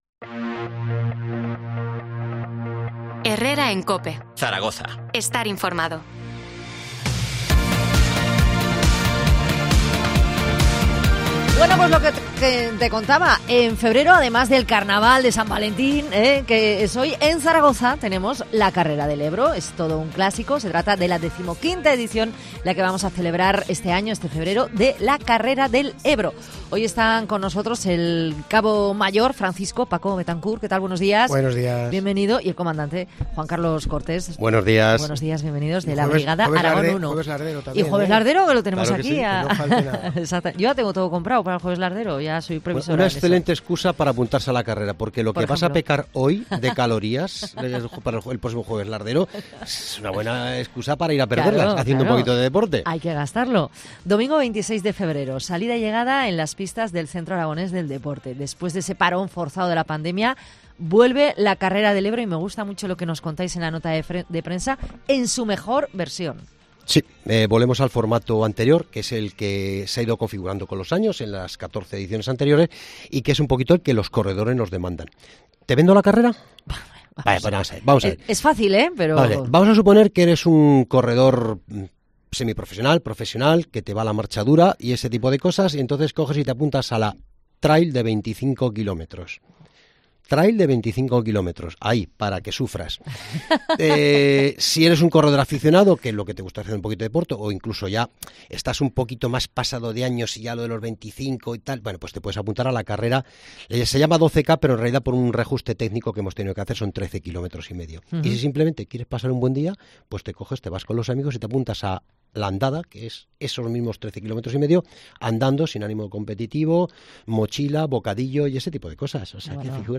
Entrevista sobre la XV edición de la Carrera del Ebro que se celebrará el 26 de febrero.